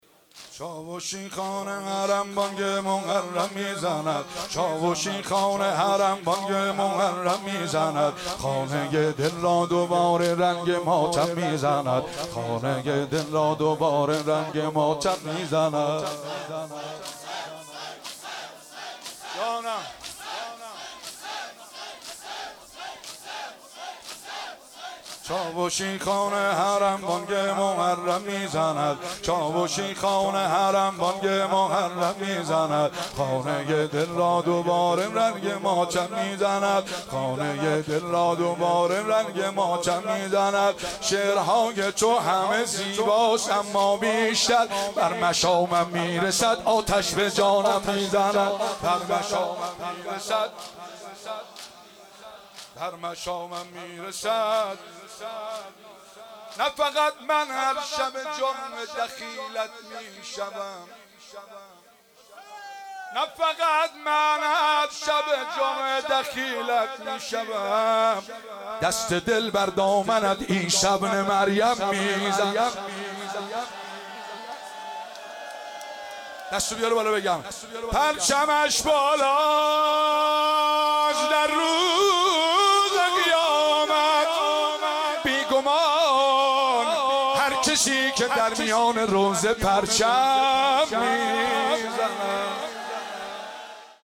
تک جدید